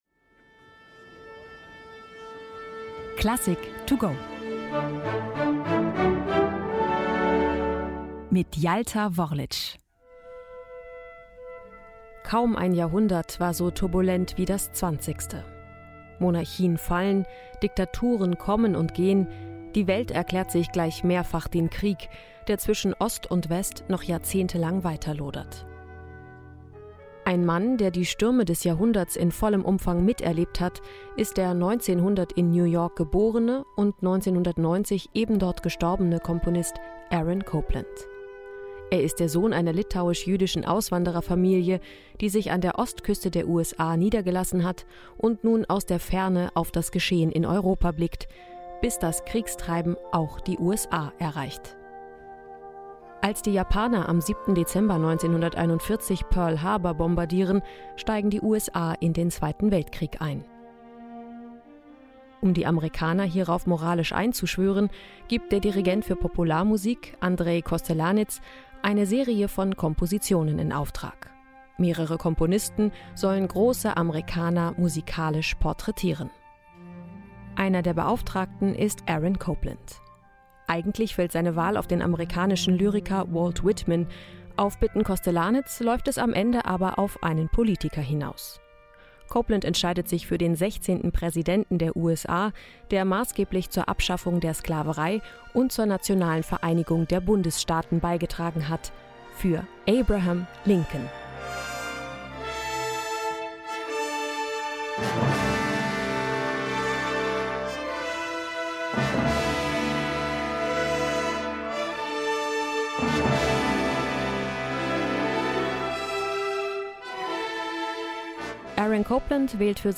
Die digitale Werkeinführung für den Weg ins Konzert: